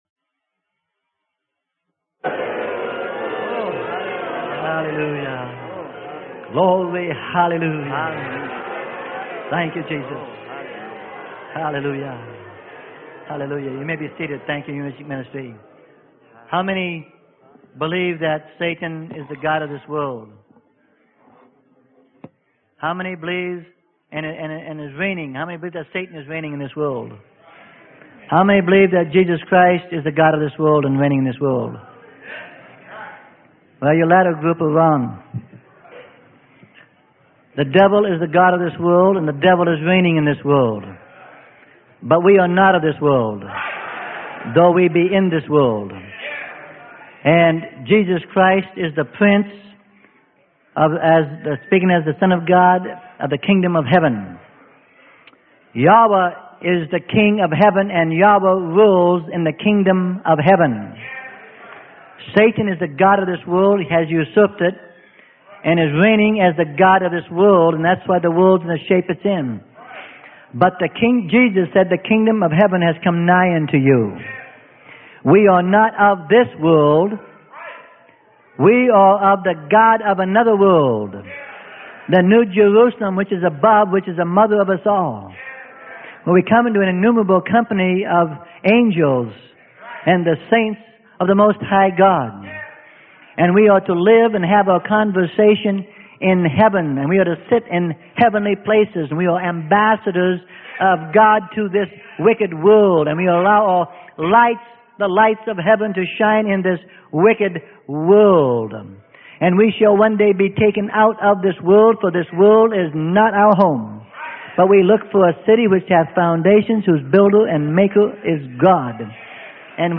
Sermon: Coming to the Birth - Freely Given Online Library